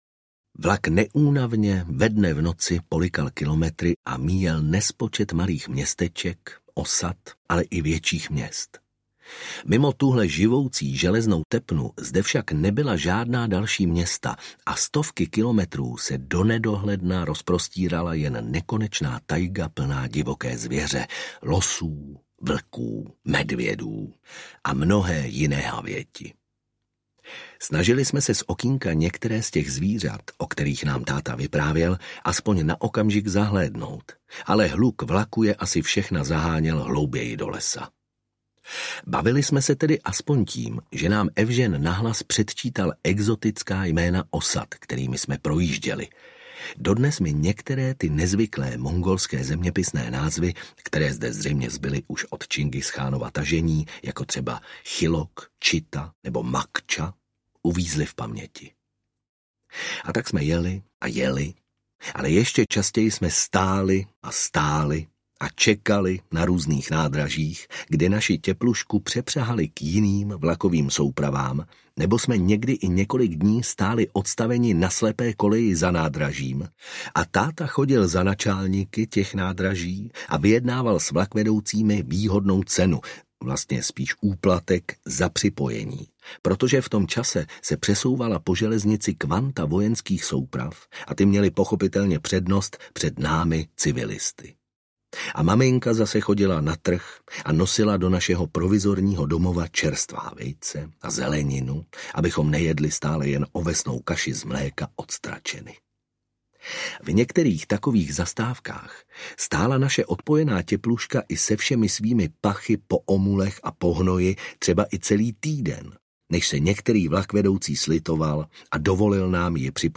Trosečník sibiřský audiokniha
Ukázka z knihy
Vyrobilo studio Soundguru.